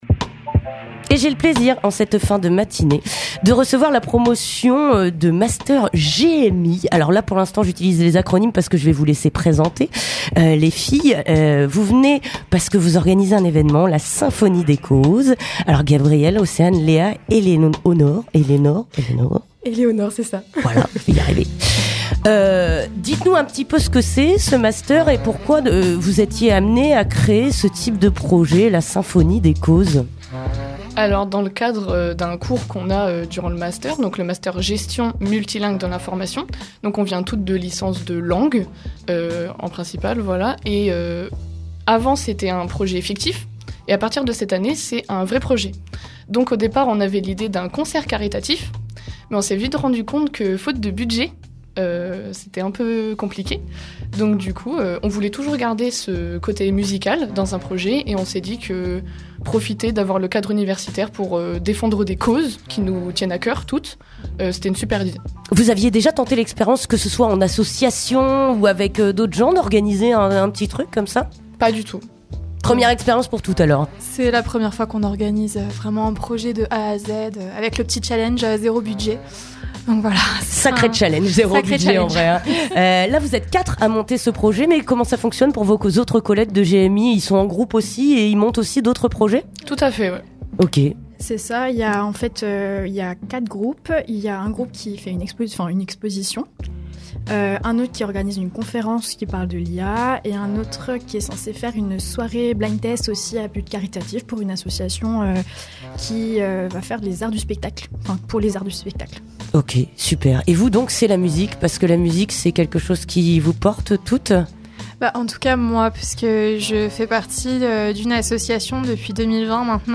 Interview des Master GMI (15:18)